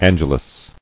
[An·ge·lus]